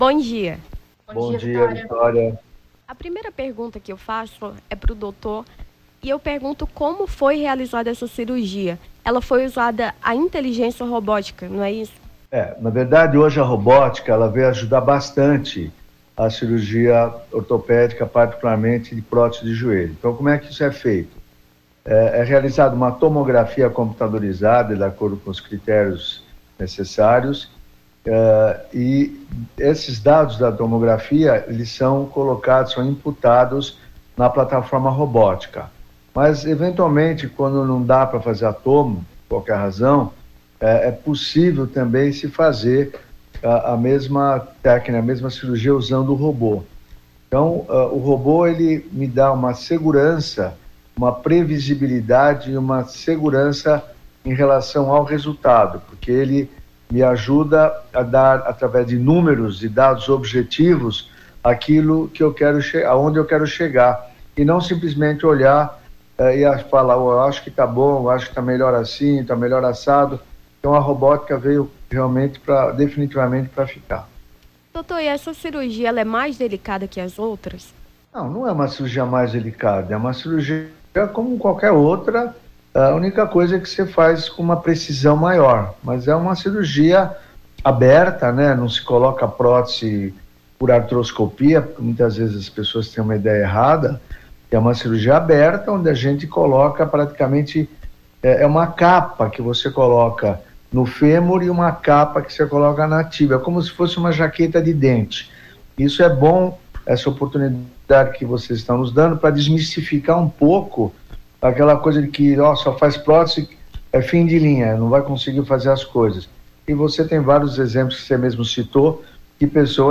Nome do Artista - CENSURA - ENTREVISTA (CIRURGIA ROBOTICA) 28-09-23.mp3